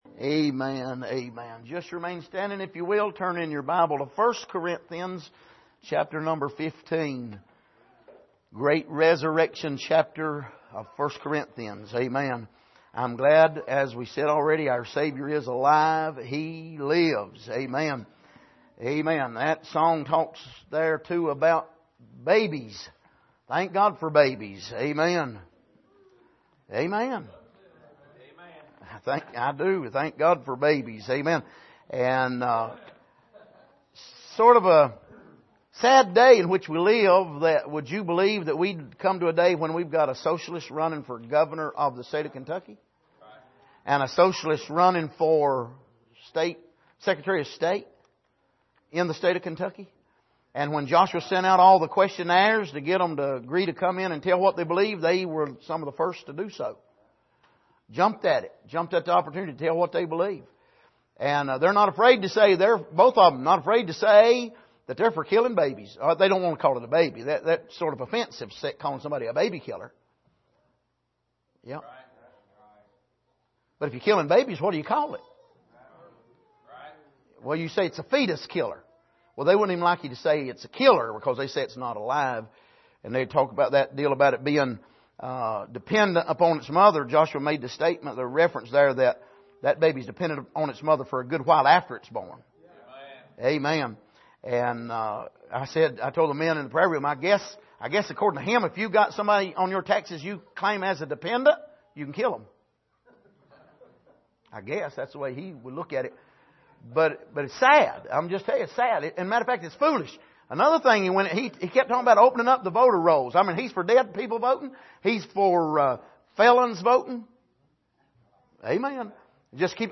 Passage: 1 Corinthians 15:1-4 Service: Sunday Morning